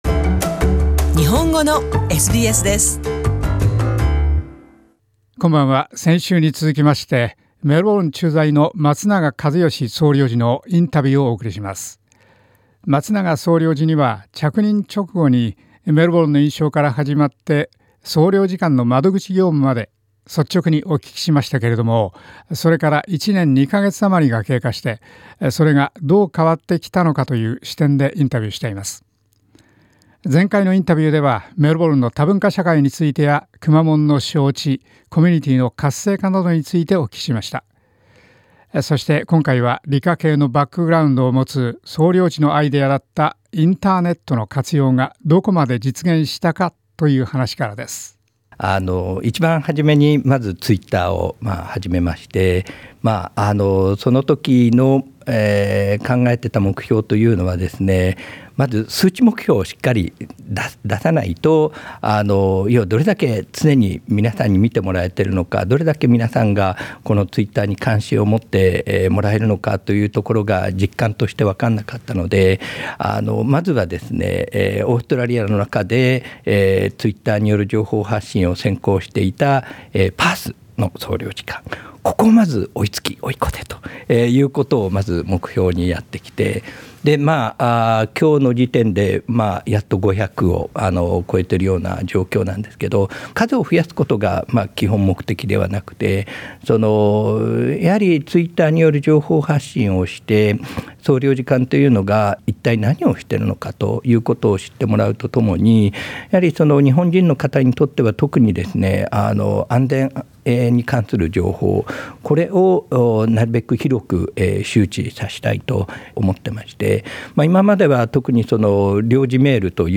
メルボルン着任から１年余り経った松永一義メルボルン総領事に、改めて総領事館のサービスや窓口業務の改善についてなどをお聞きした。１年前のインタビューのフォローアップの後半。